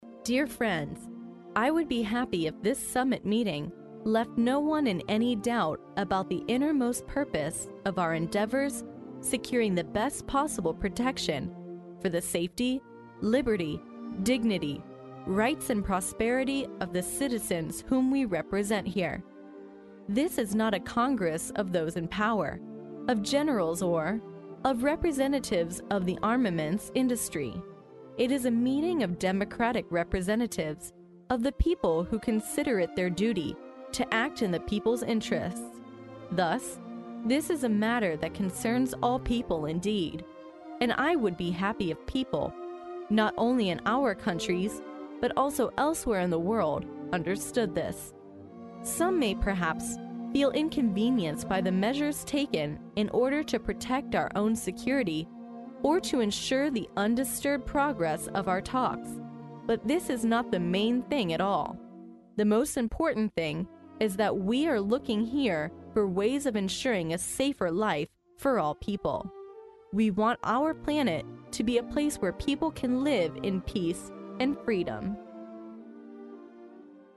历史英雄名人演讲 第59期:捷克总统哈韦尔在北约首脑会议闭幕式上致欢迎词(2) 听力文件下载—在线英语听力室